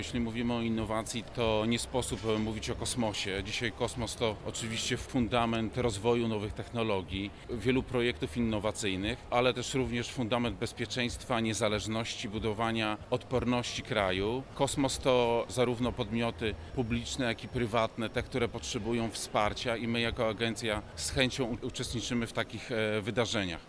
Na kilka dni przed ponownym otwarciem, na wrocławskim lotnisku zagościło Dolnośląskie Forum Innowacyjne.
Na wydarzeniu obecny jest również płk Marcin Mazur, wiceprezes Polskiej Agencji Kosmicznej, który zwrócił uwagę na to, że inwestowanie w technologie kosmiczne to dziś gwarancja bezpieczeństwa i suwerenności technologicznej.